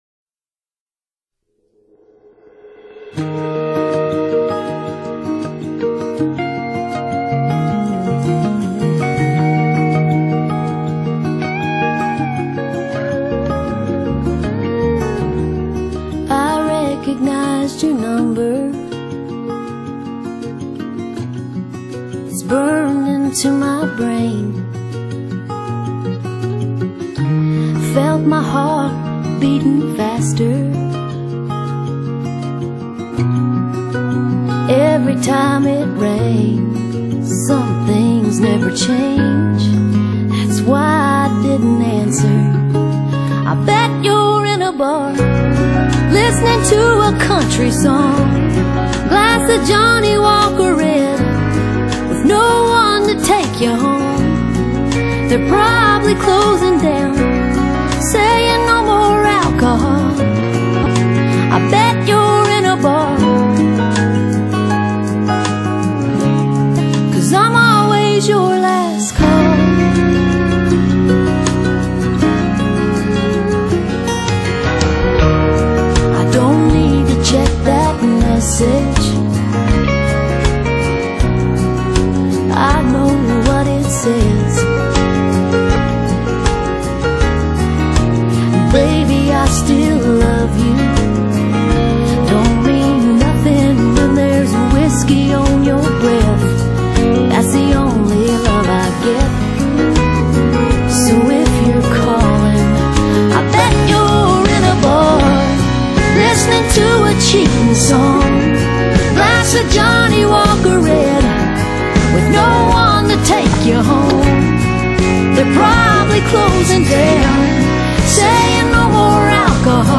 Country | MP3 320 Kbps | Incl.